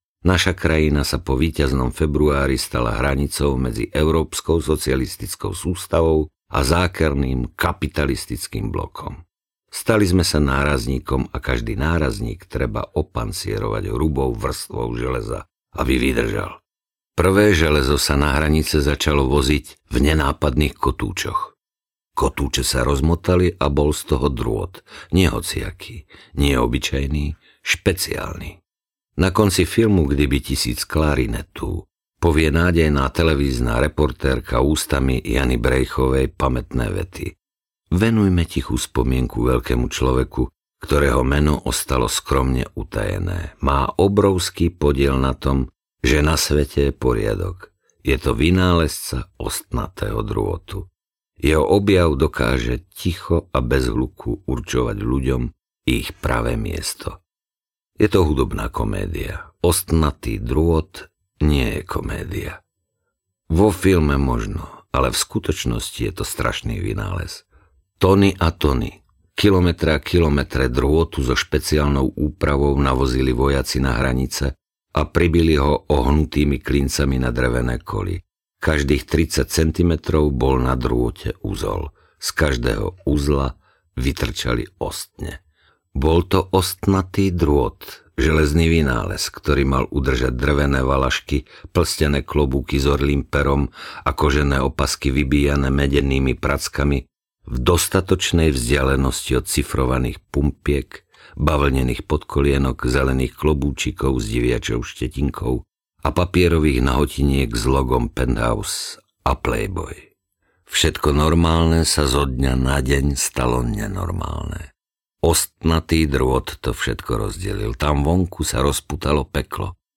Uzol audiokniha
Ukázka z knihy